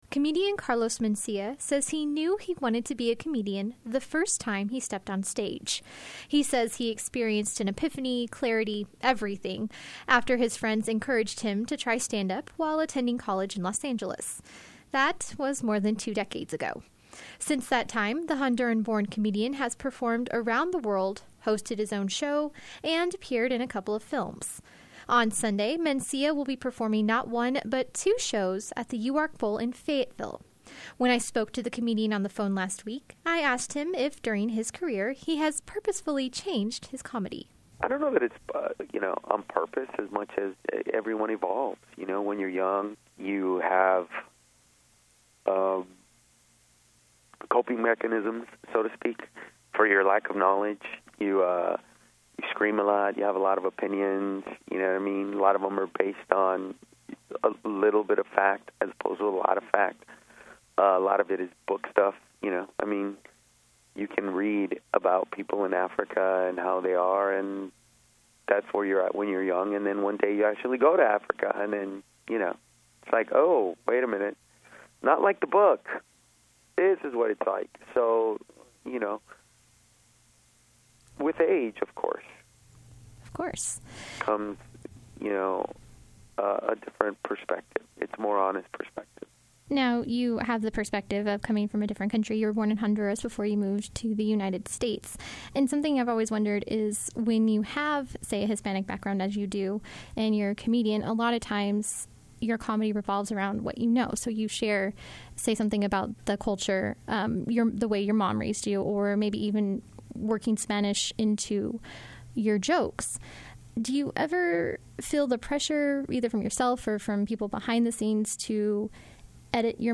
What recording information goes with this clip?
talked with him over the phone about the evolution of his comedy style among other things.